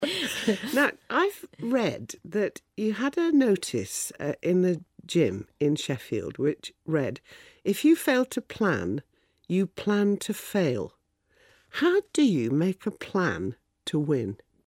【英音模仿秀】尼古拉·亚当斯（2） 听力文件下载—在线英语听力室